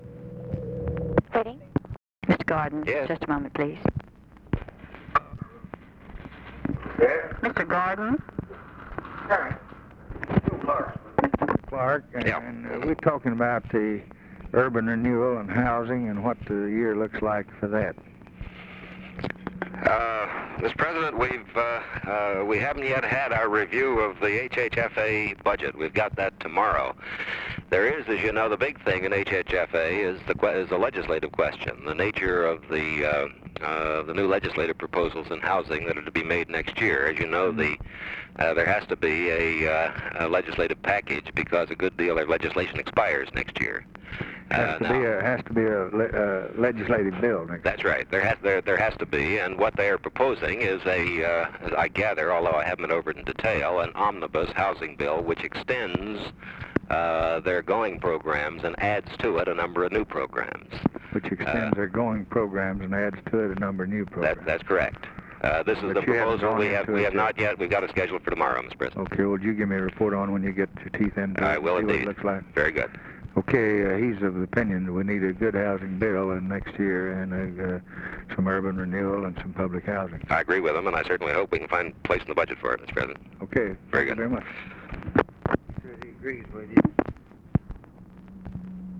Conversation with KERMIT GORDON, December 10, 1963
Secret White House Tapes